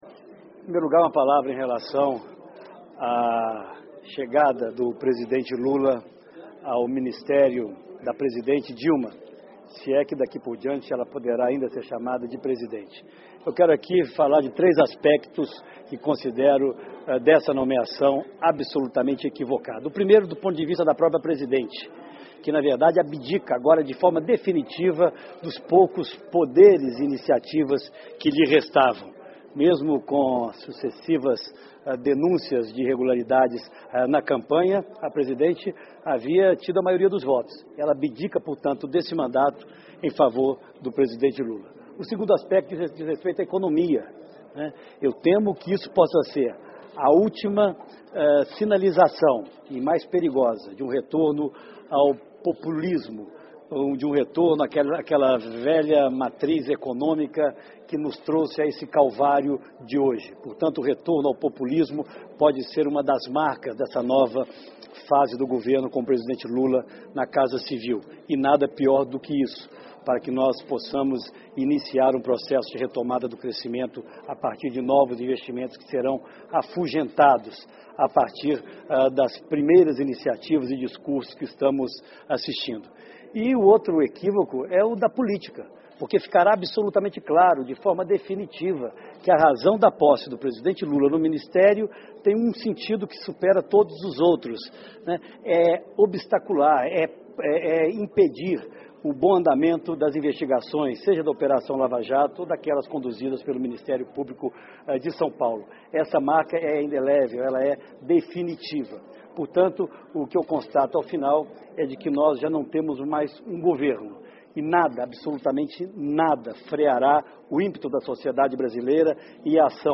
Entrevista coletiva em Brasília